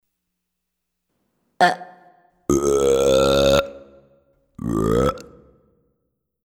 41a Burps(Burps)
41a Burps.mp3